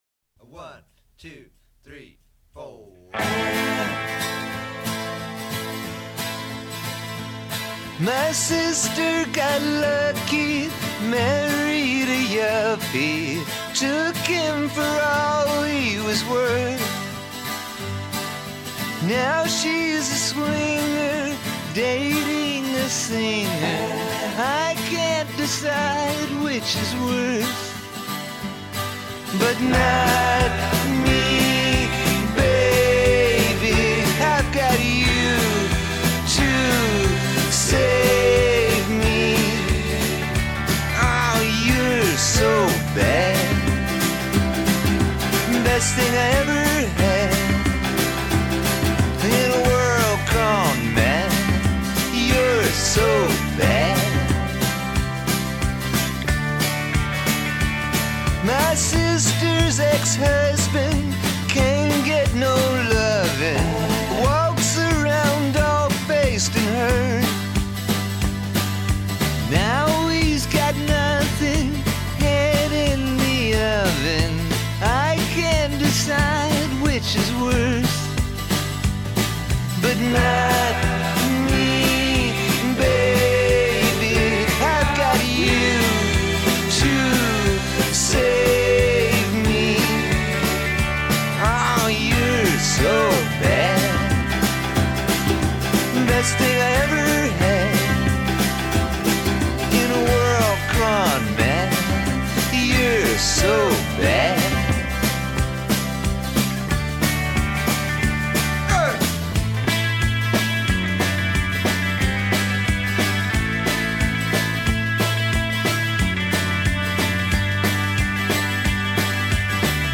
southern rock
oddball rockabilly masterpiece